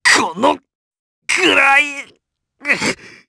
Gladi-Vox_Dead_jp.wav